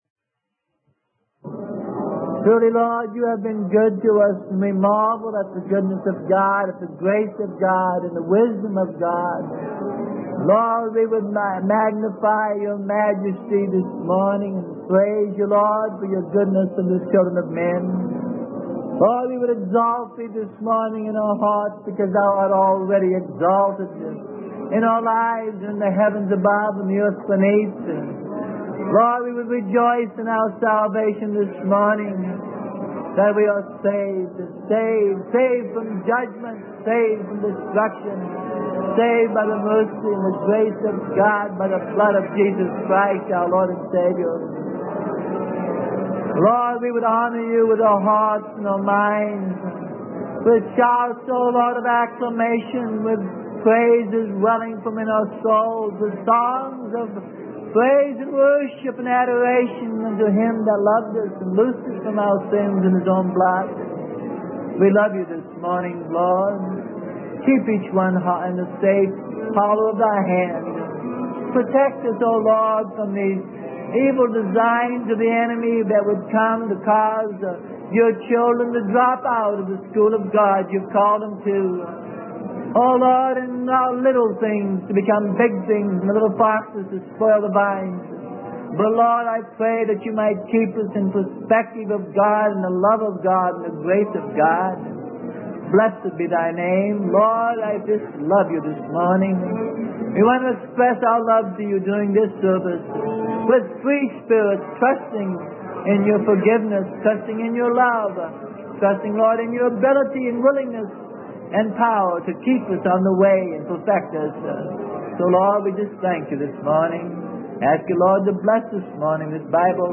Sermon: How to be Led of God - Part 13 - Tongues and Interpretations - Freely Given Online Library